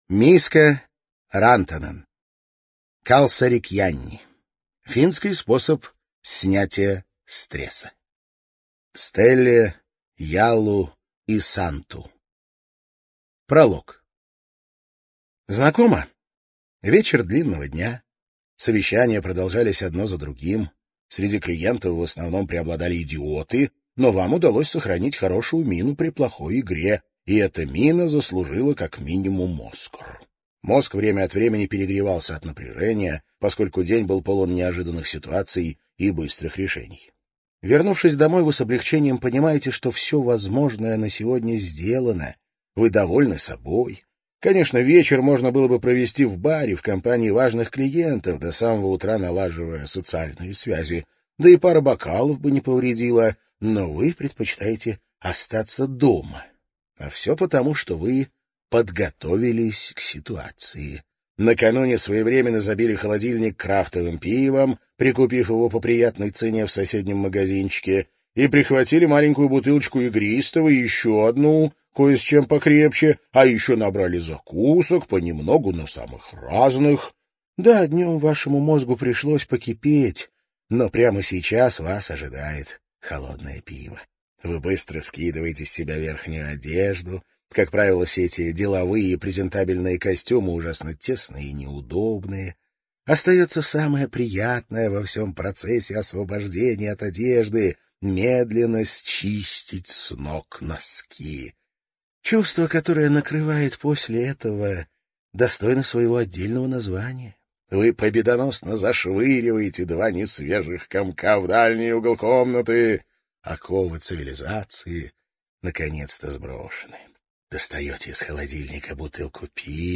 ЖанрПсихология